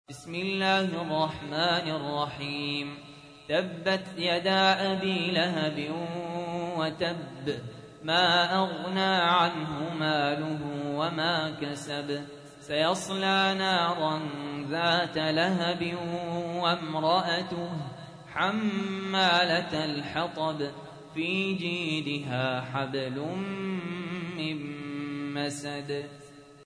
تحميل : 111. سورة المسد / القارئ سهل ياسين / القرآن الكريم / موقع يا حسين